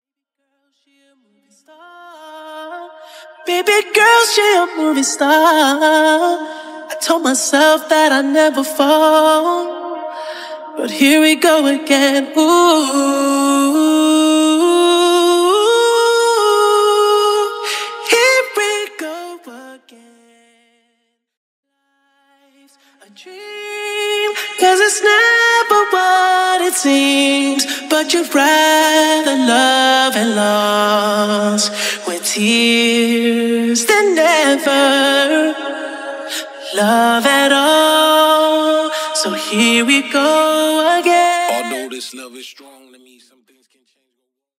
DEMO HERE :